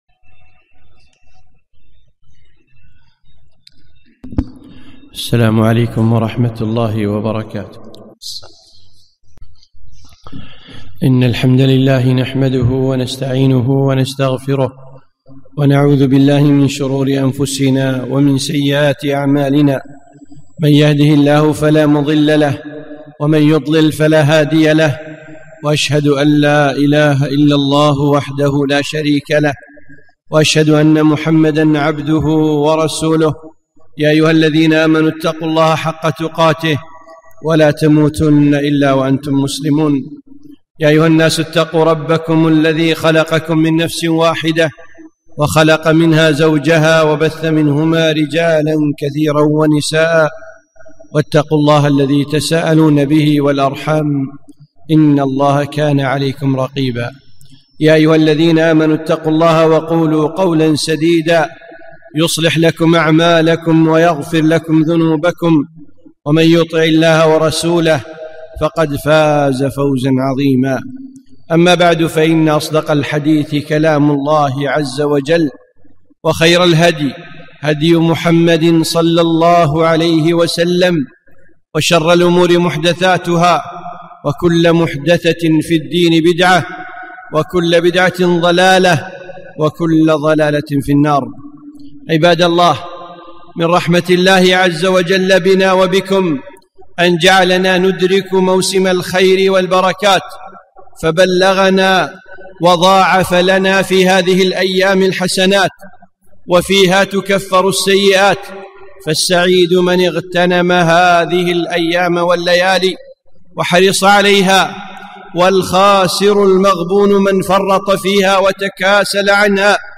خطبة - فضائل العشر الأول من ذي الحجة